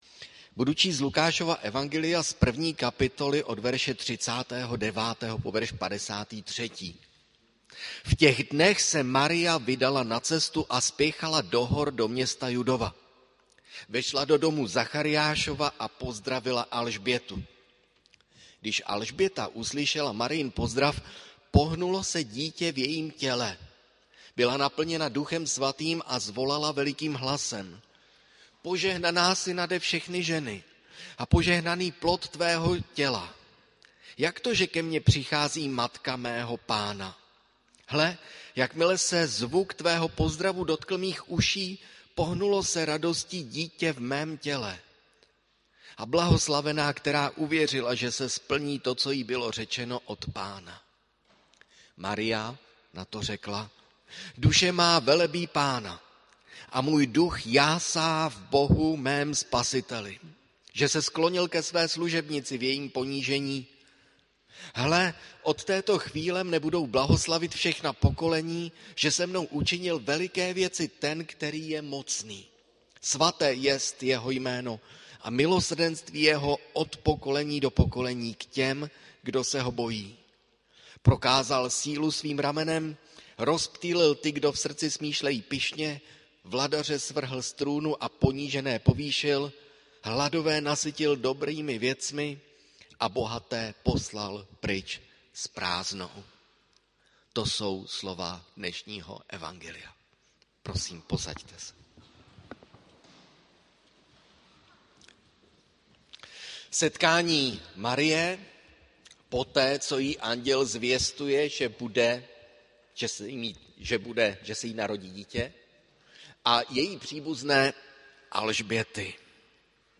Audio kázání zde